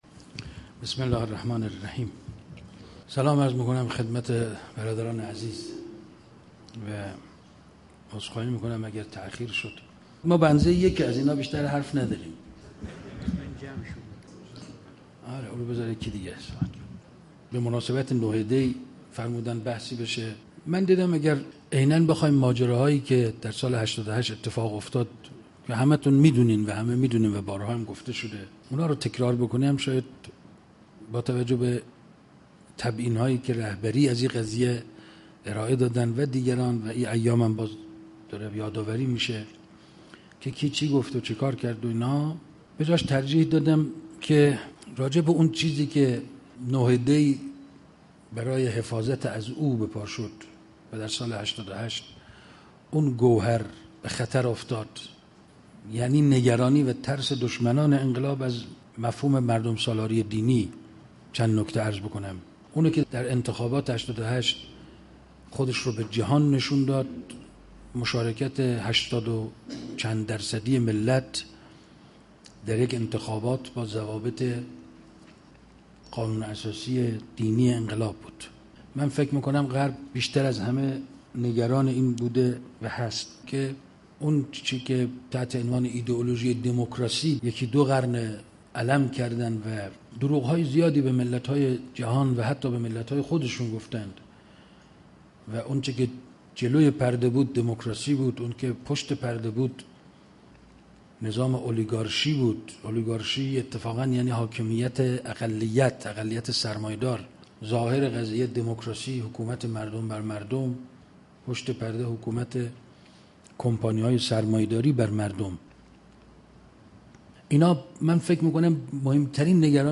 سخنرانی رحیم پور ازغدی با موضوع "انتخابات و حق مردم" +صوت